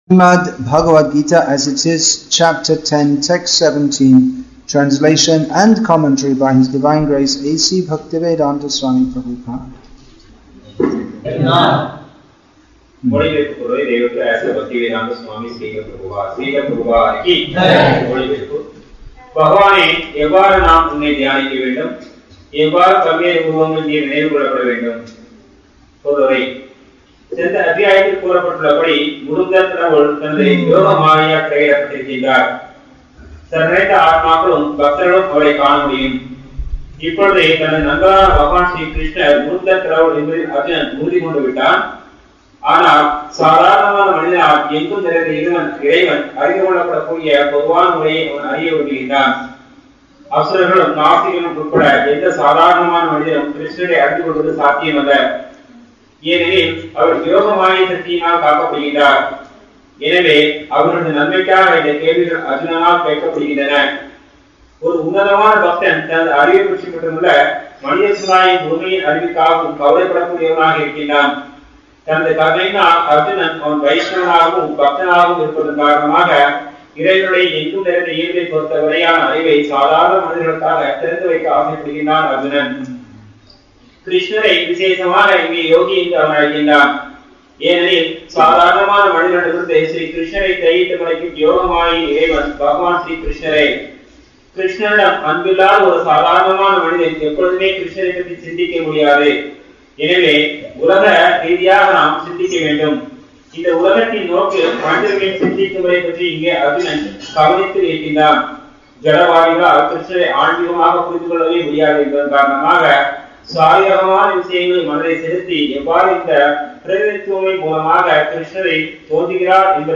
English with தமிழ் (Tamil) Translation; Vellore, Tamil Nadu , India Bhagavad-gītā 10.17